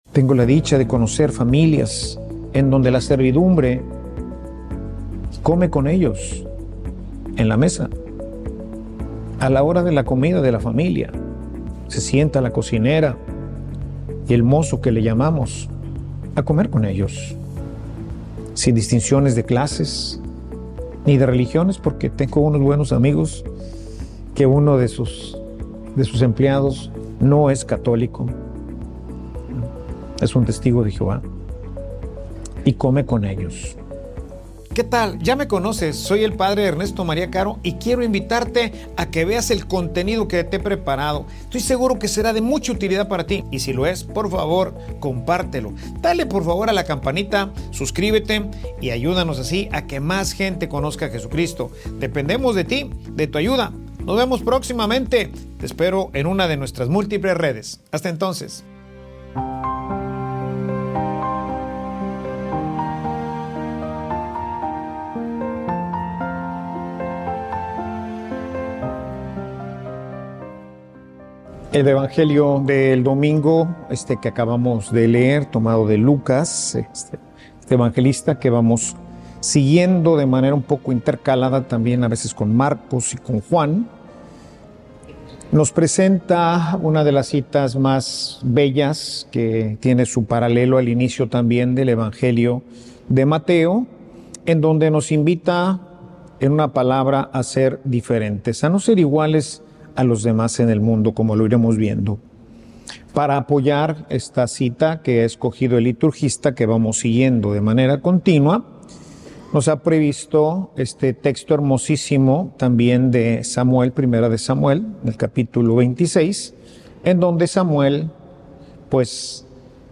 Homilia_3_formas_de_amar.mp3